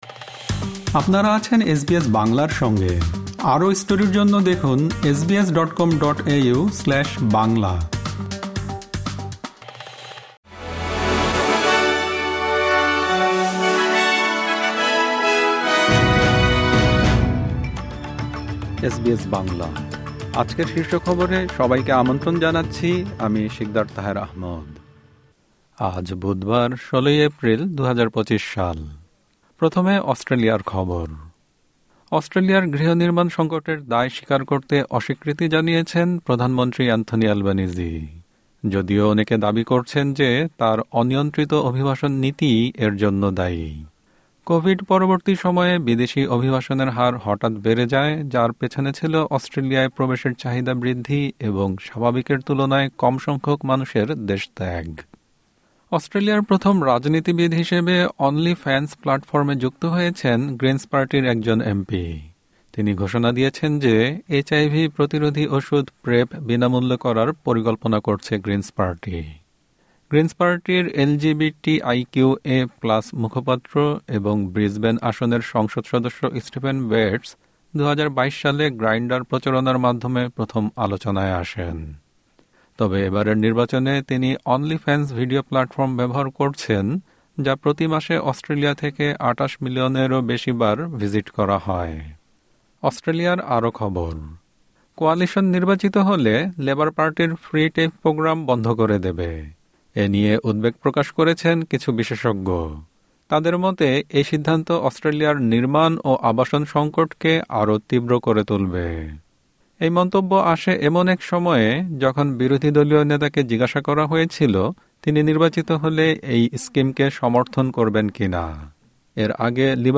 এসবিএস বাংলা শীর্ষ খবর: ১৬ এপ্রিল, ২০২৫